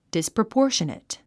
発音 dìsprəpɔ́ːrʃənət ディスプロポーショネト
disproportionate.mp3